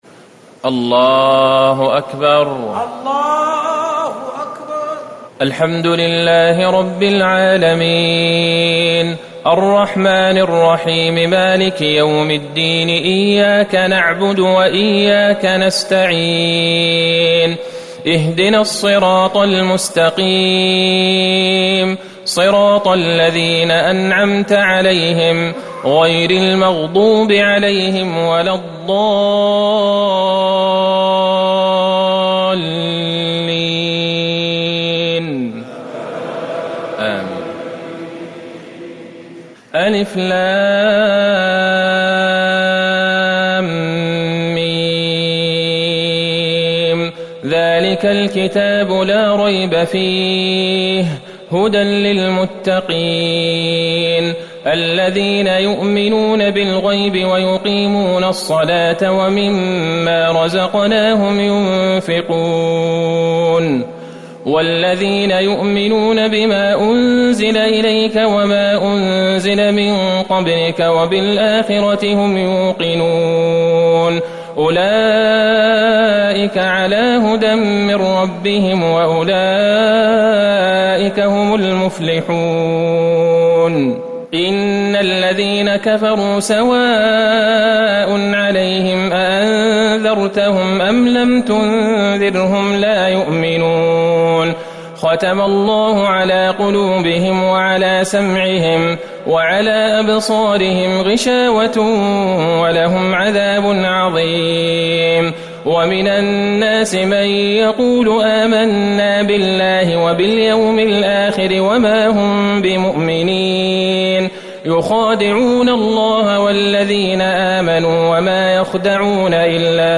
ليلة ١ رمضان ١٤٤٠هـ من سورة البقرة ١-٨٢ > تراويح الحرم النبوي عام 1440 🕌 > التراويح - تلاوات الحرمين